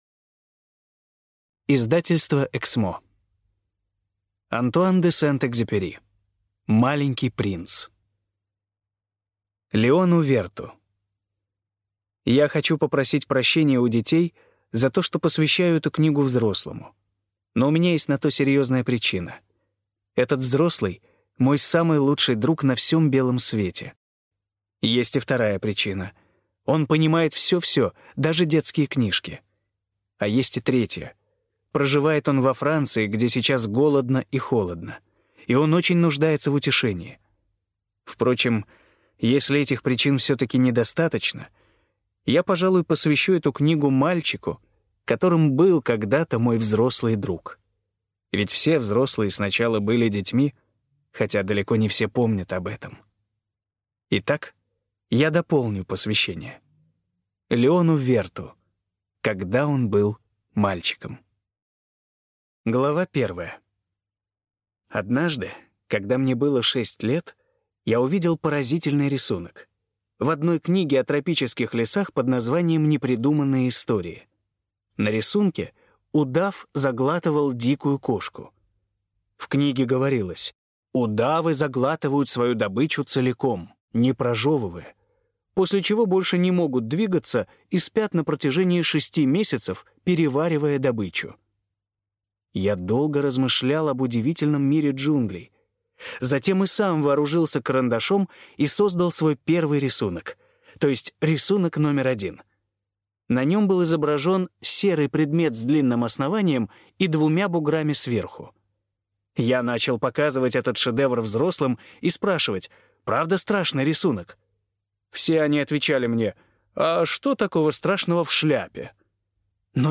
Аудиокнига Маленький принц | Библиотека аудиокниг